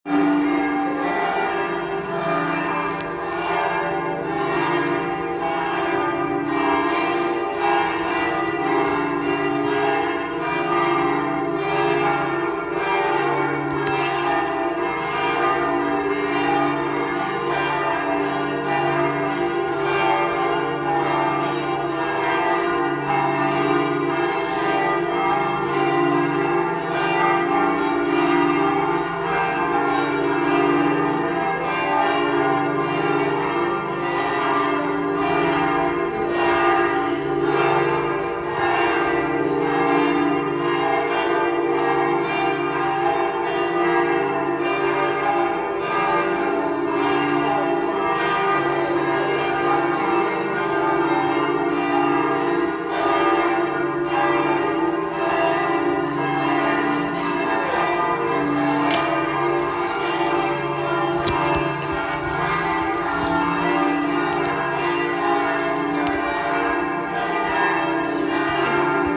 paduabells.mp3